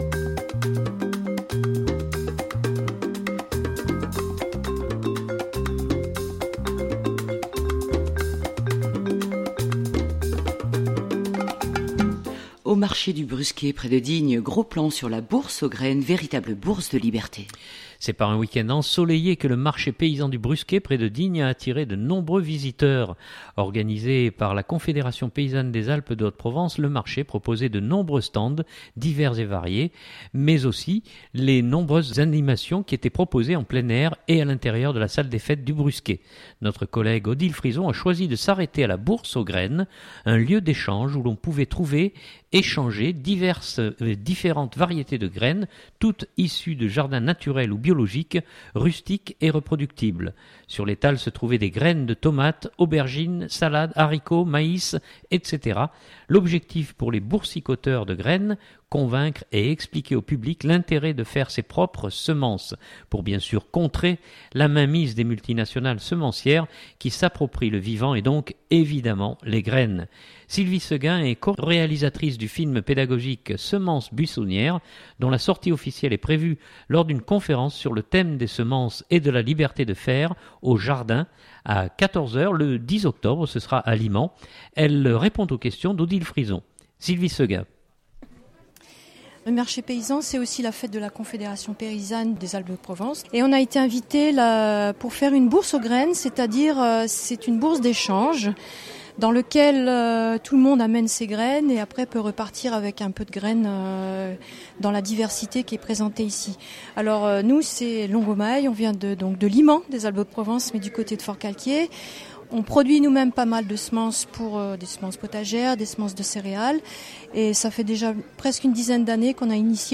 Au marché du Brusquet, gros plan sur la bourse aux graines, bourse de liberté